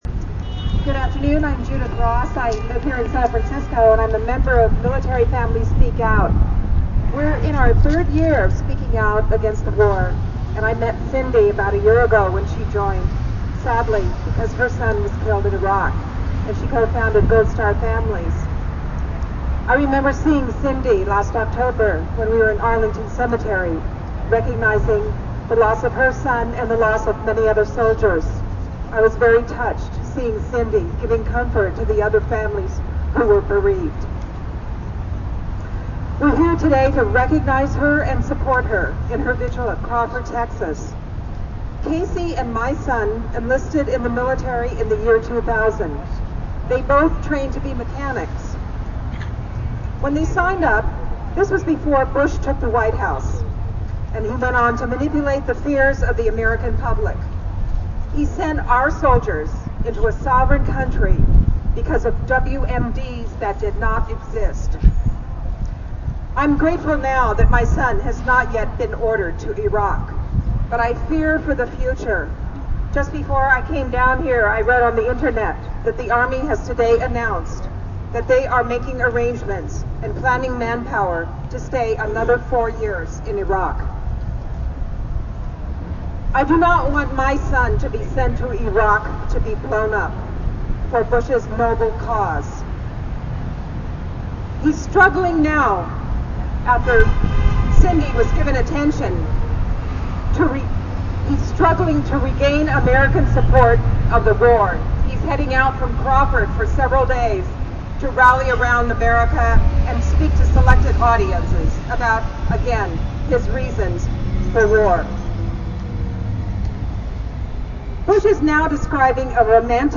Audio From Camp Casey SF Anti-War VIgil